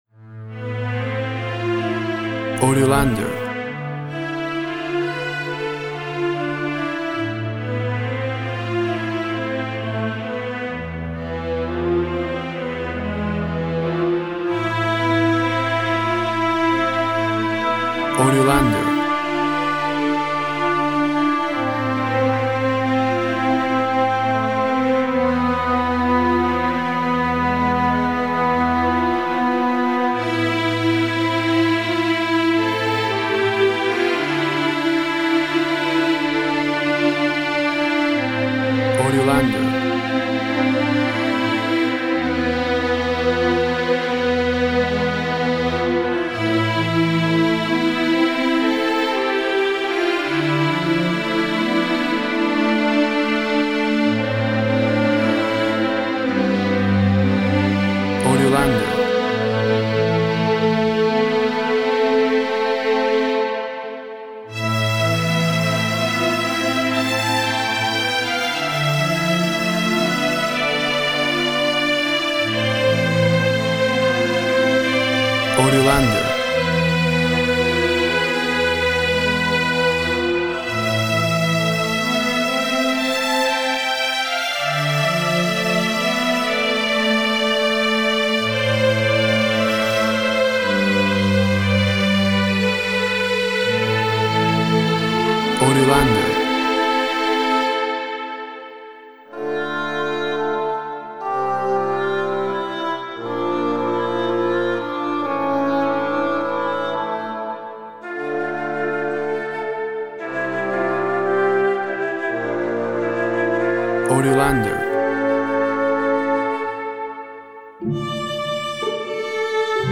Sounds of symphony orchestra plays a mournful work.
Tempo (BPM) 68/48